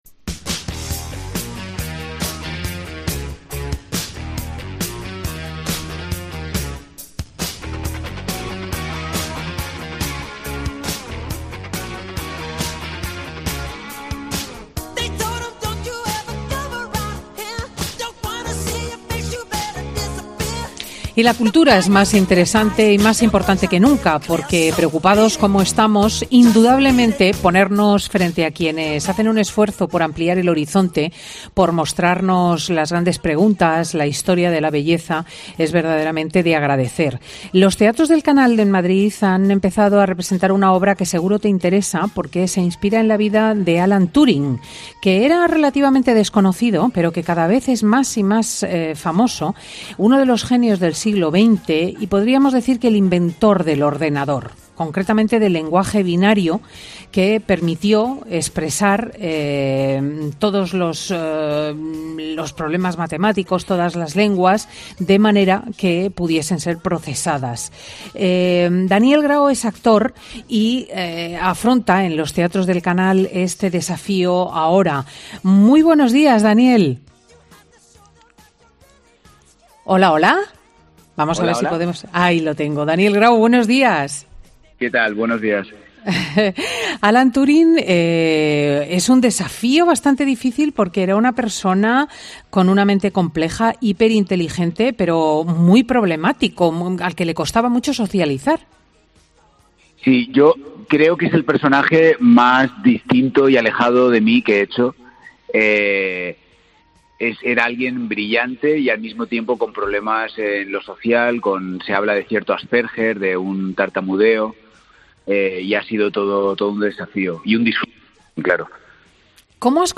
El actor ha estado en Fin de Semana con Cristina para hablar de su papel en la obra de teatro que recrea la vida de Alan Turing